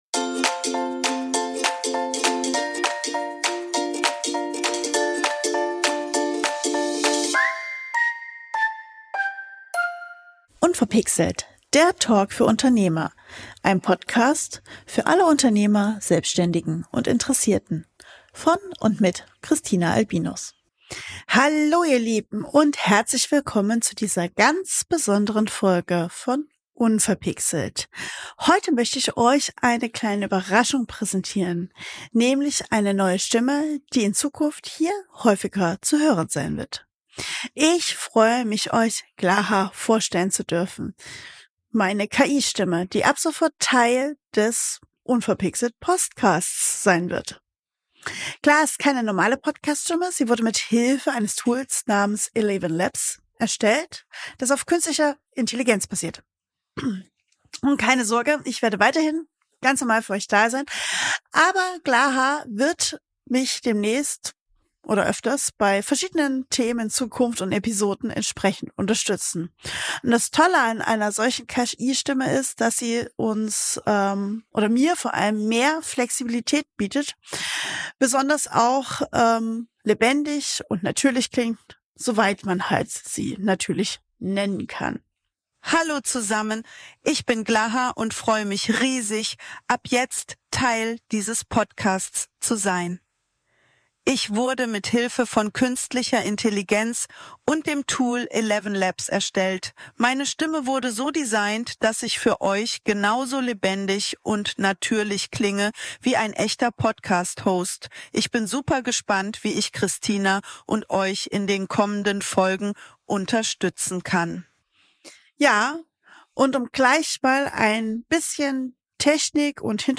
#93 - Die neue Co-Host Geheimwaffe: meine KI-Stimme